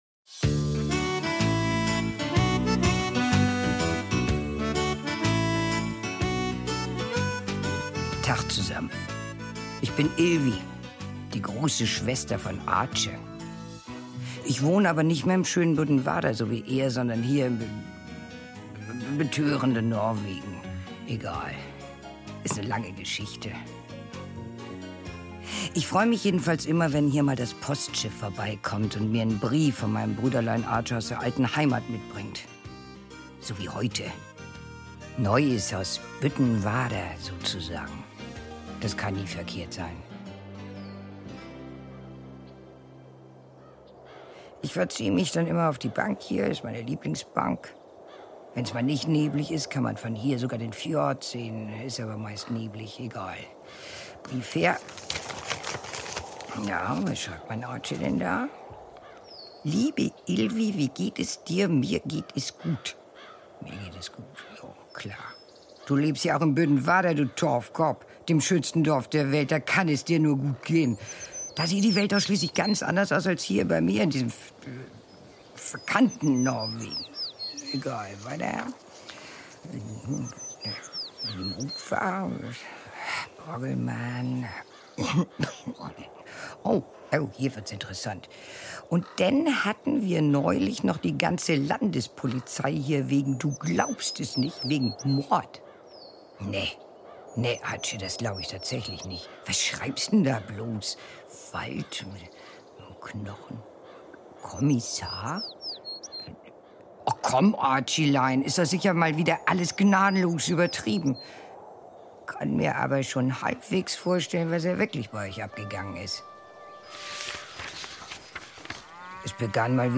Büttenwarder-Hörspiel: War wohl Mord ~ Neues aus Büttenwarder Podcast